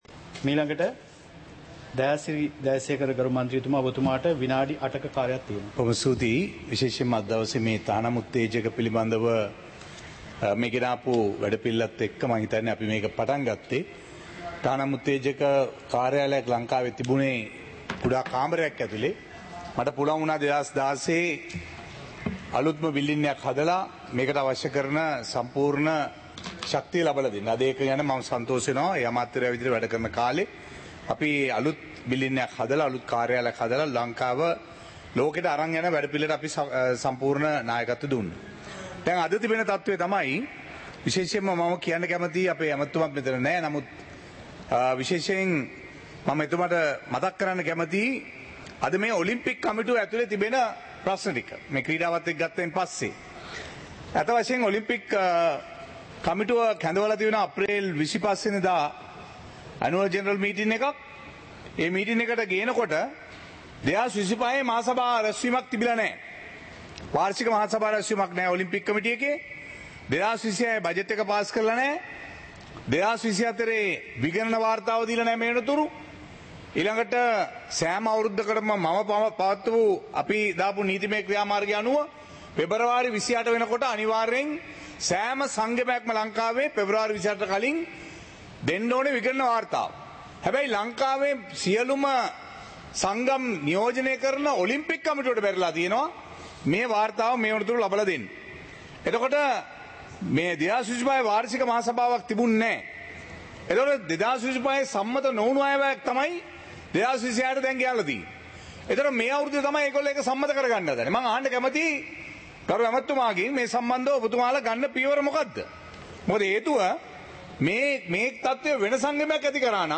සභාවේ වැඩ කටයුතු (2026-04-07)